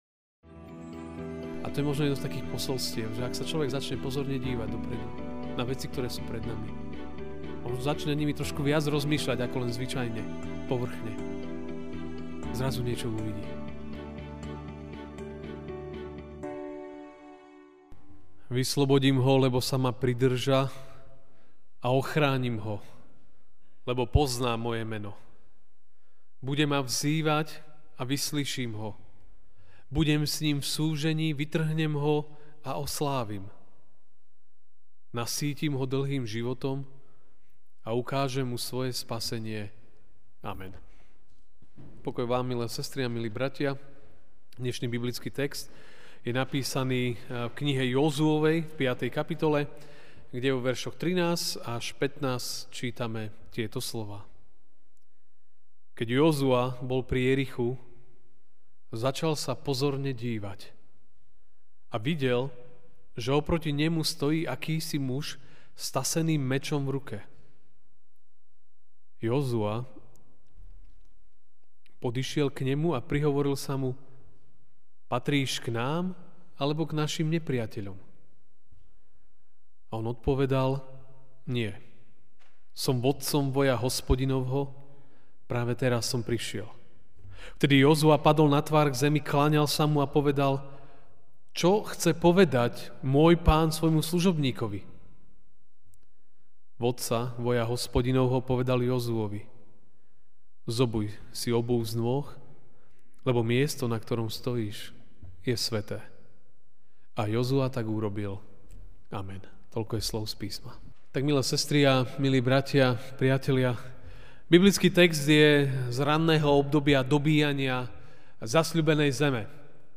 - Evanjelický a.v. cirkevný zbor v Žiline
Večerná kázeň: Dobre sa dívaj a nájdeš posilnenie.